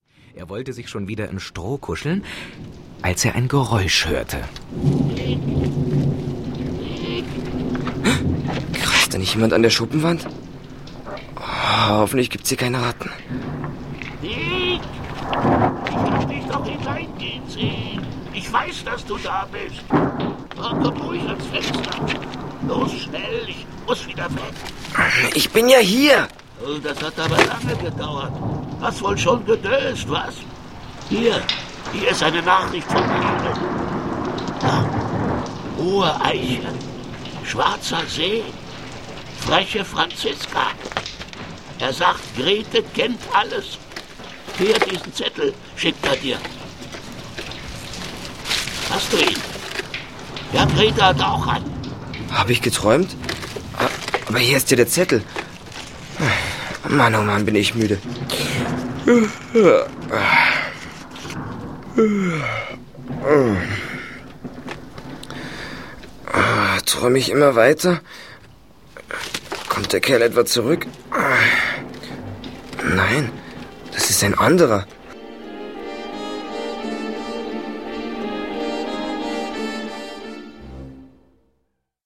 Ravensburger Fünf Freunde - Folge 17: Auf großer Fahrt ✔ tiptoi® Hörbuch ab 6 Jahren ✔ Jetzt online herunterladen!